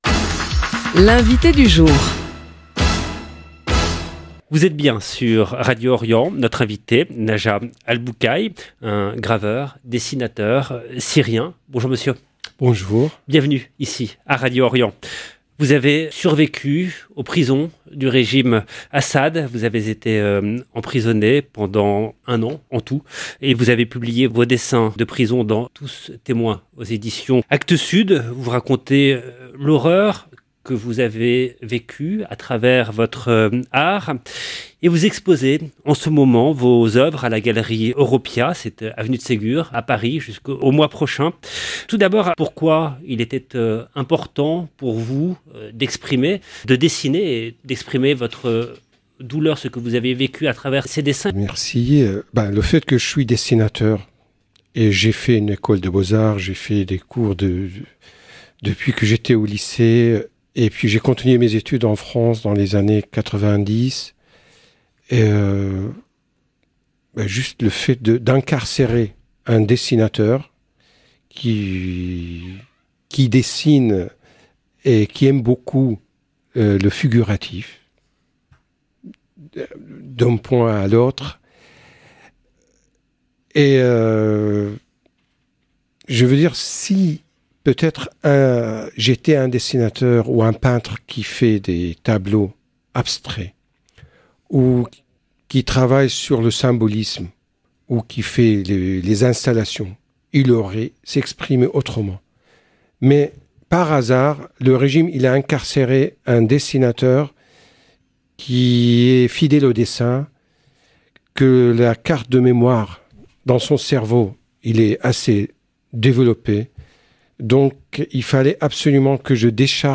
L’INVITÉ DU JOUR